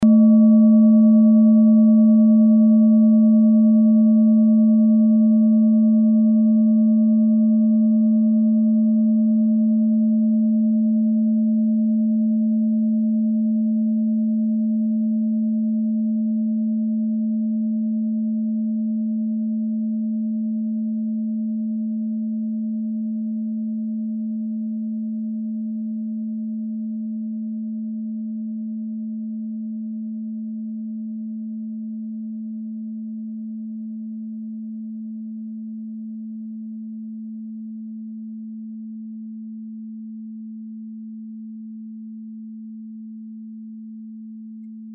Tibet Klangschale Nr.2
Sie ist neu und wurde gezielt nach altem 7-Metalle-Rezept in Handarbeit gezogen und gehämmert.
Hörprobe der Klangschale
(Ermittelt mit dem Filzklöppel oder Gummikernschlegel)
klangschale-tibet-2.mp3